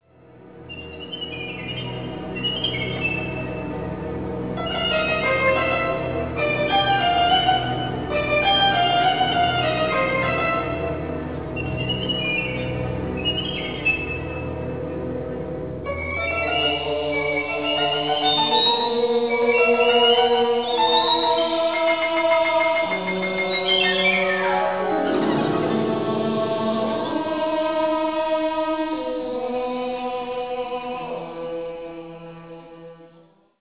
voice 2
violin 2
flute 1
synthesizer 2
percussion 2